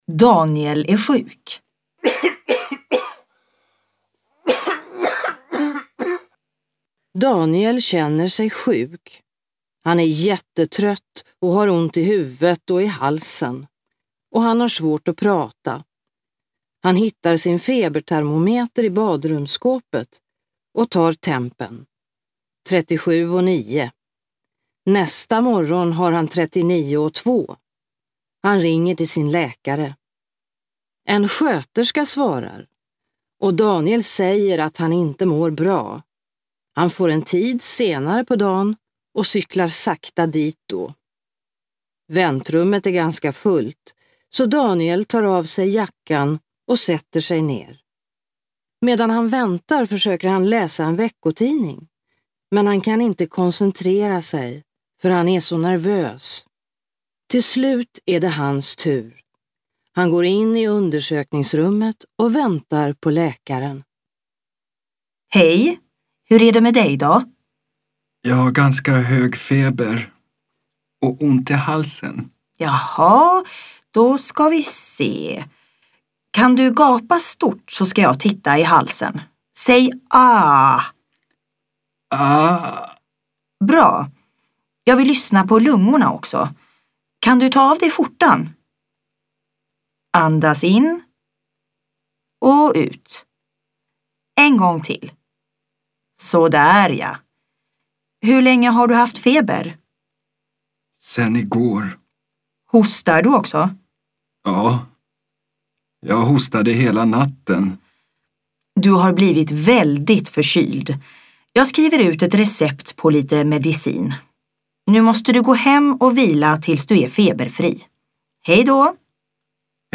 Luisteroefening